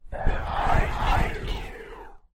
Behind You Horror Sound Effect Free Download